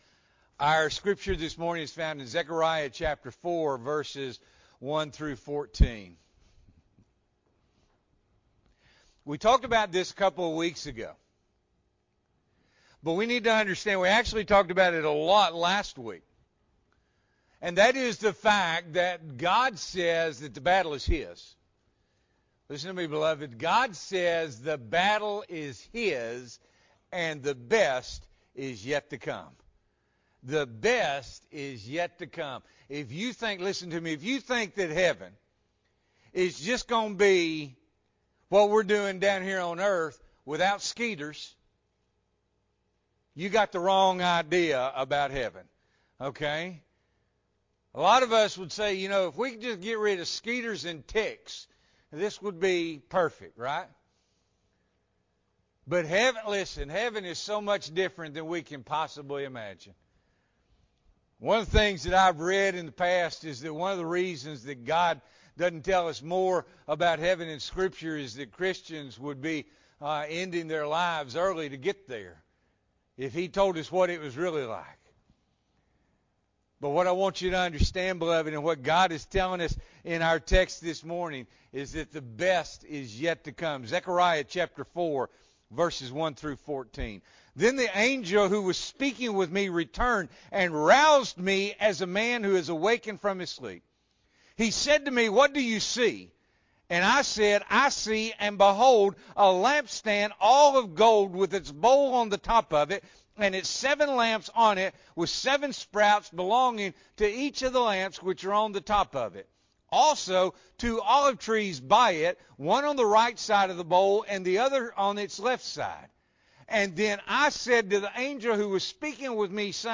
November 14, 2021 – Morning Worship